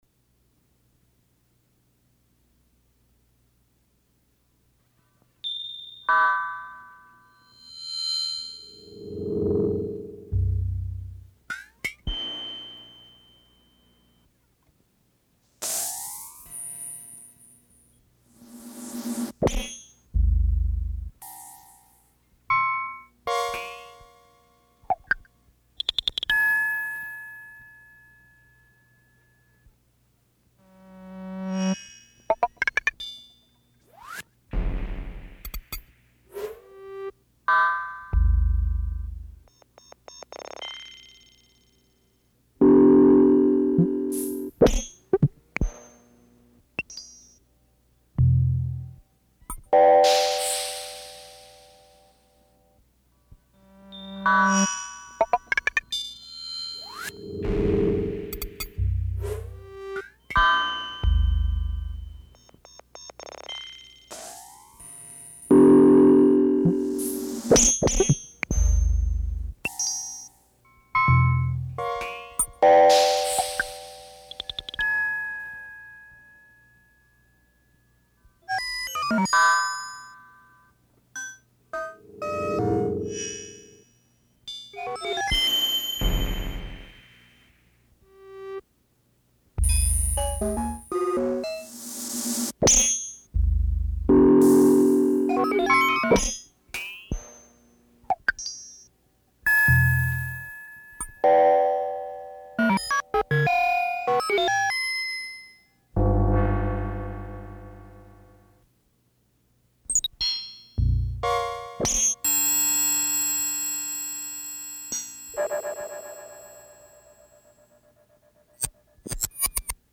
Banda Magnética, Partitura y Análisis.
Instrumentación: Electroacústica